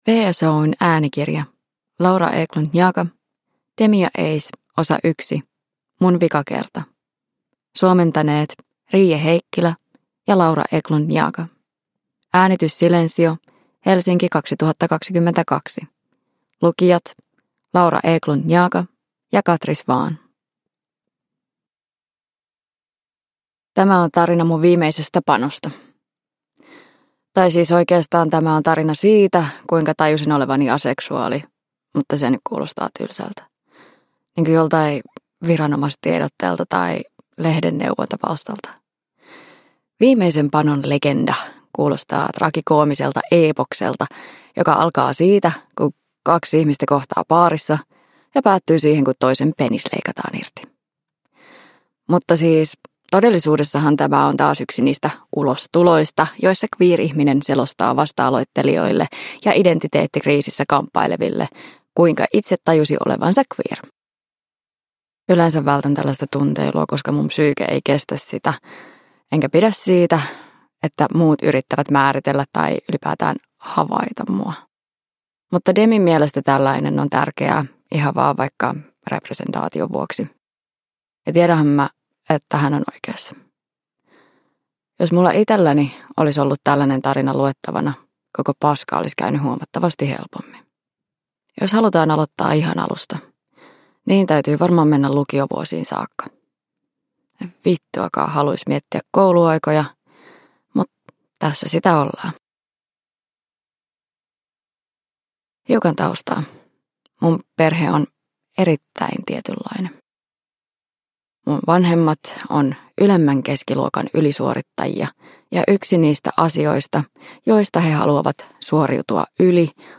Raikas kuusiosainen audiosarja siitä kuinka outoa rakkaus on.